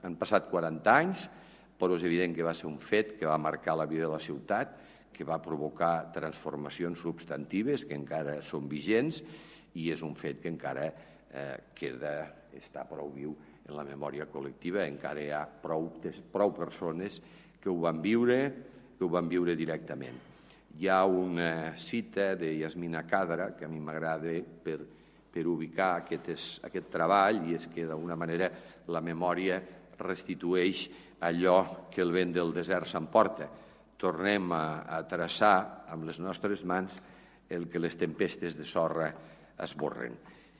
tall-de-veu-de-lalcalde-de-lleida-miquel-pueyo-sobre-els-actes-per-commemorar-els-40-anys-de-la-riuada-a-la-ciutat